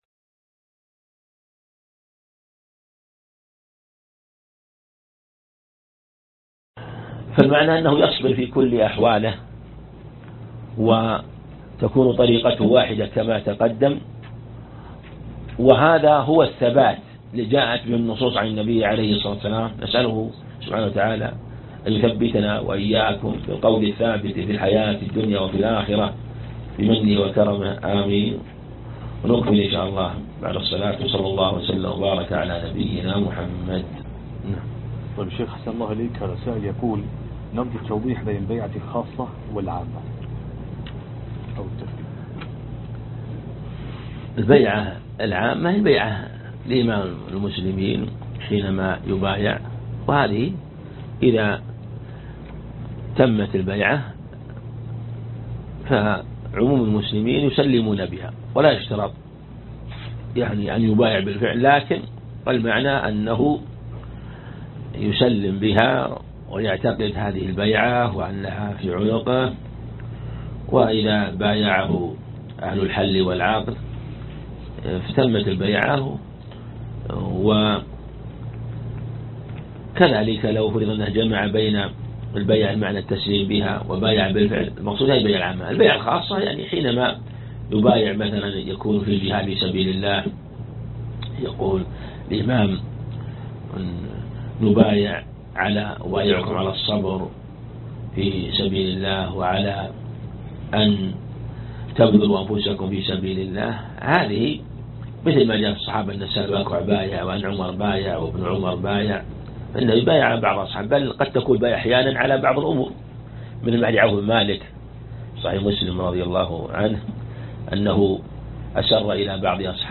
الدرس الثالث - كتاب الفتن من التجريد الصريح لأحاديث الجامع الصحيح للزبيدي